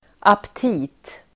Uttal: [apt'i:t]